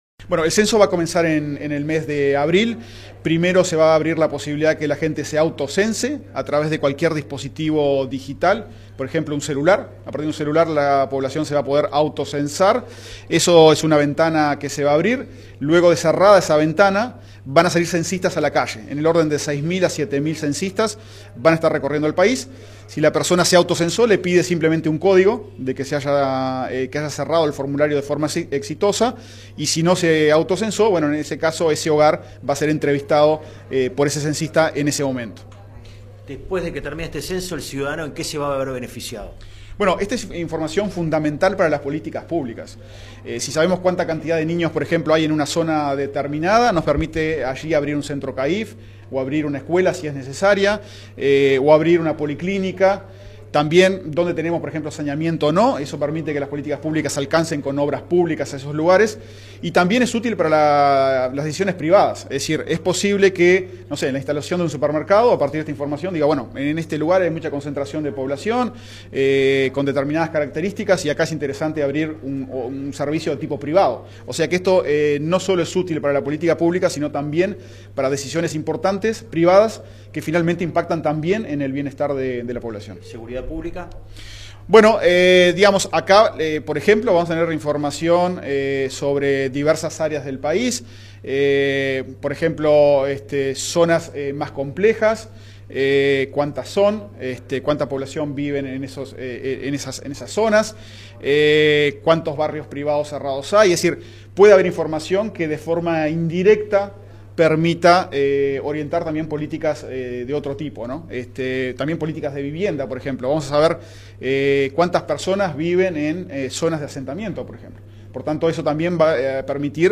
Declaraciones del director del INE, Diego Aboal, en el lanzamiento del Pre Censo 2023
Tras el evento, el director del INE, Diego Aboal, dialogó con la prensa.